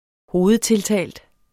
Udtale [ -ˌtelˌtæˀld ]